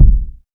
Kicks
KICK.130.NEPT.wav